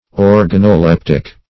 Search Result for " organoleptic" : The Collaborative International Dictionary of English v.0.48: Organoleptic \Or`ga*no*lep"tic\, a. [F. organoleptique, fr. Gr.
organoleptic.mp3